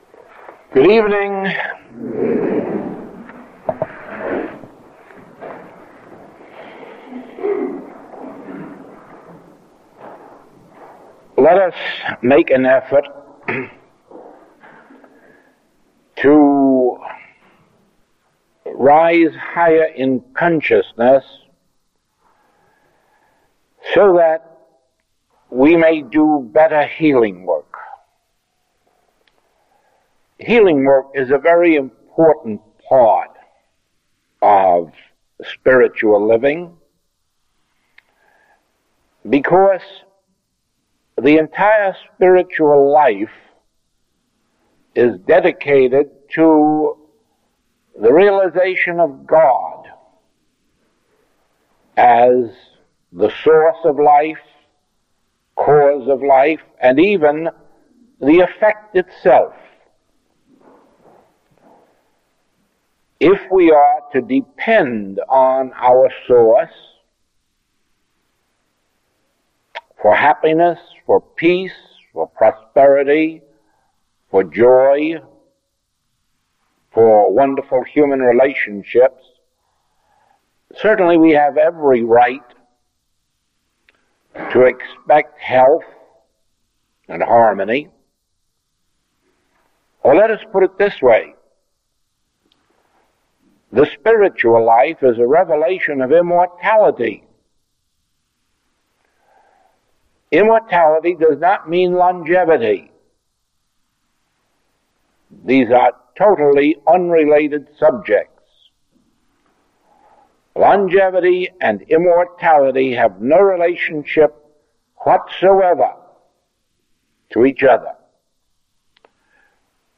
Recording 489A is from the 1962 London Closed Class.